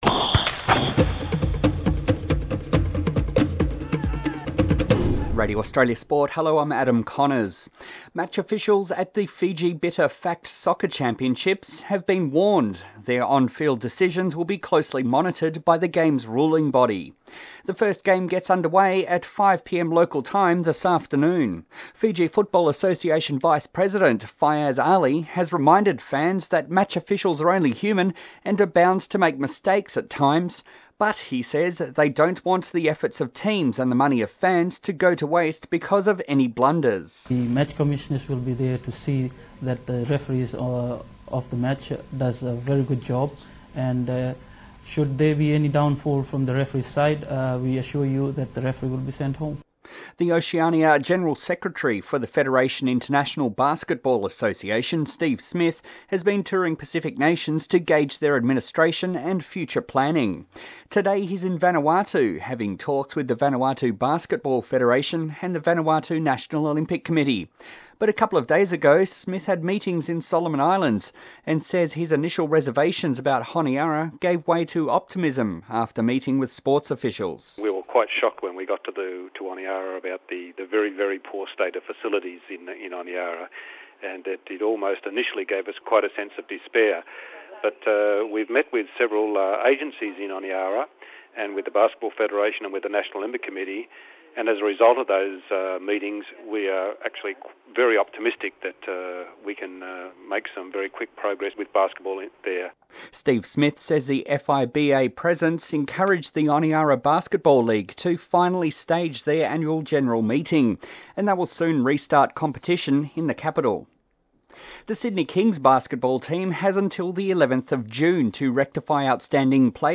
Five minute bulletin of Radio Australia Sport.